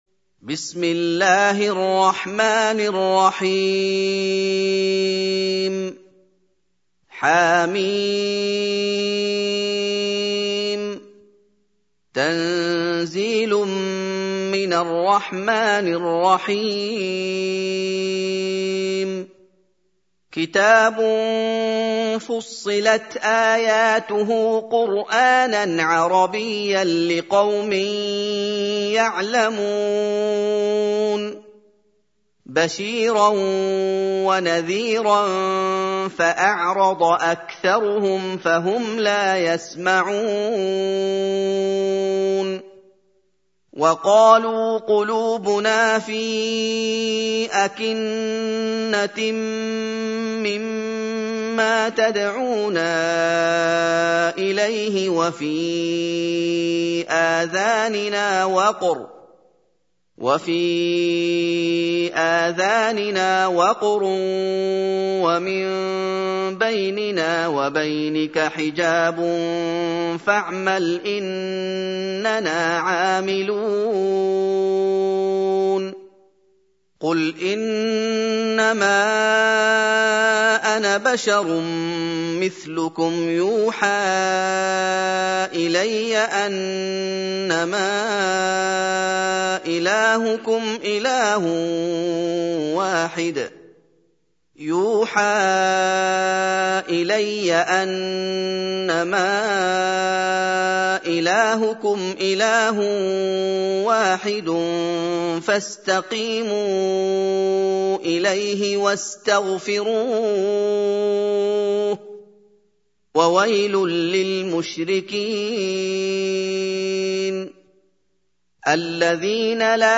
سورة فصلت | القارئ محمد أيوب